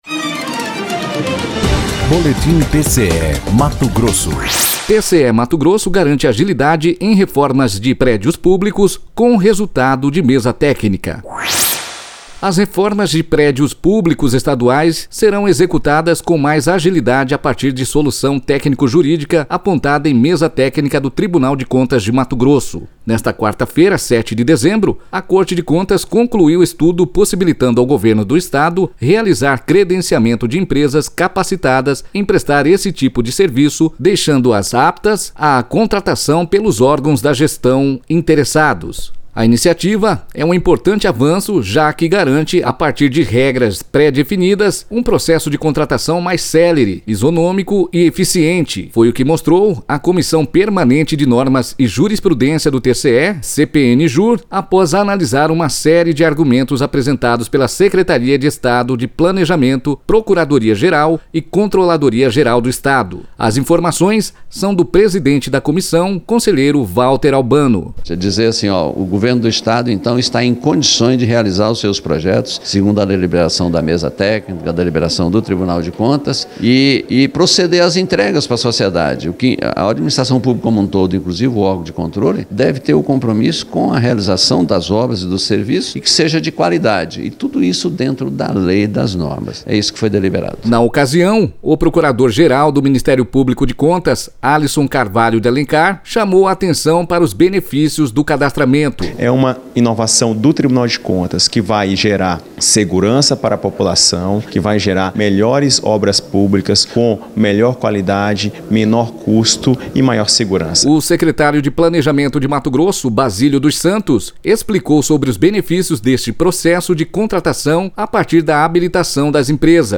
Sonora: Valter Albano – conselheiro presidente da CPNJur
Sonora: Alisson Carvalho de Alencar - procurador-geral do MPC
Sonora:  Basílio dos Santos - secretário de Planejamento de Mato Grosso
Sonora:  Emerson Hayashida - controlador-geral do Estado